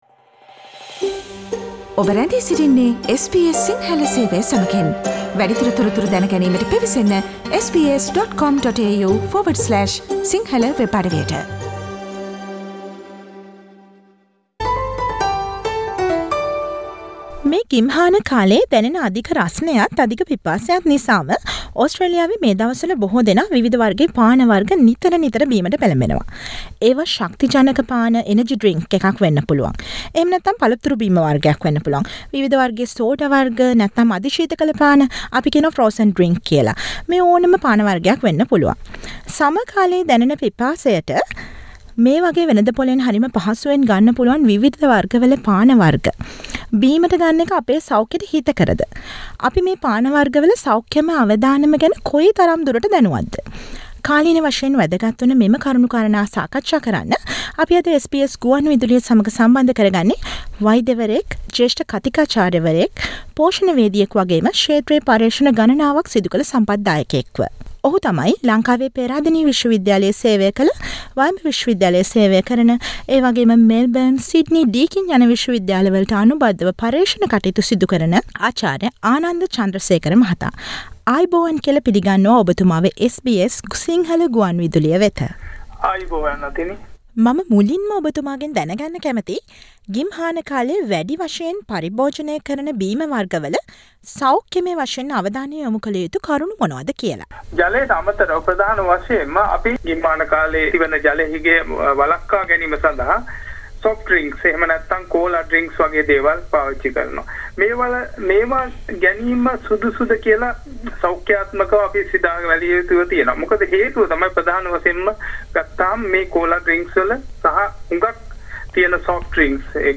SBS සිංහල ගුවන් විදුලිය සිදු කළ සාකච්ඡාවට